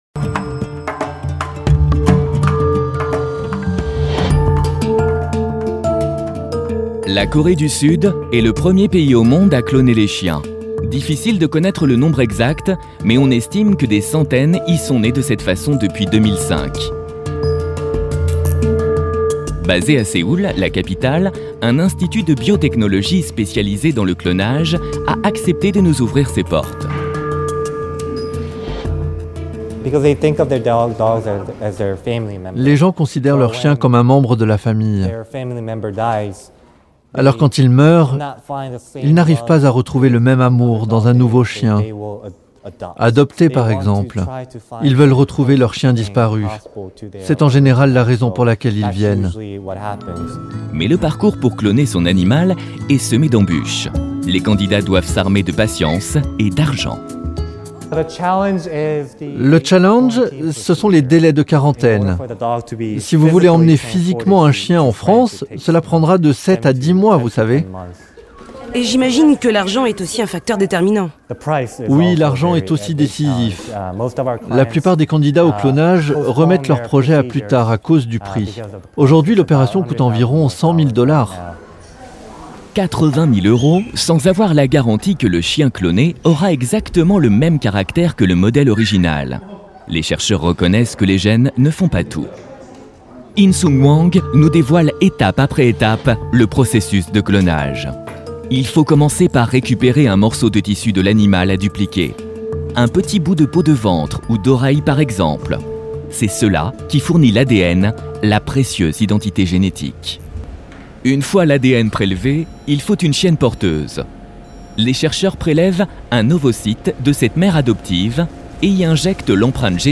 VOIX OFF émission
Sa voix a su traverser les époques sans jamais perdre de sa pertinence, de sa chaleur ni de son impact.